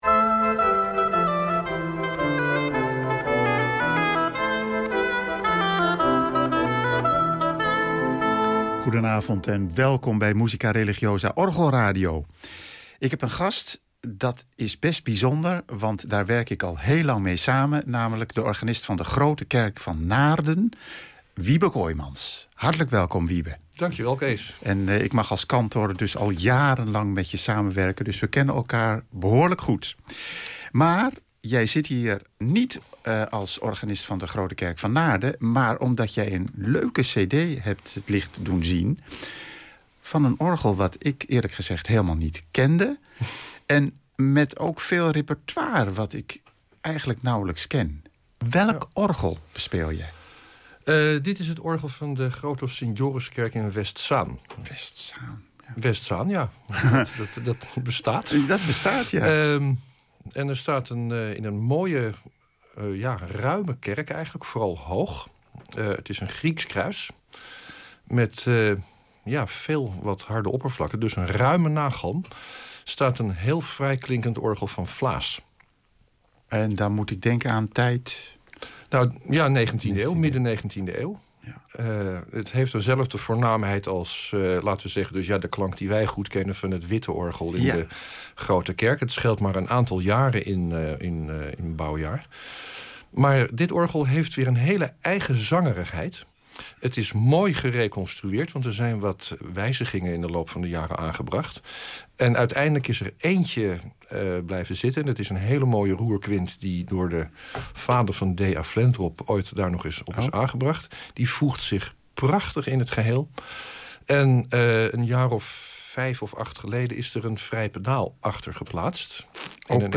Het betreft een opname voor de IKON van 7 juli 2013
Dit programma is al een aantal jaren geleden opgenomen.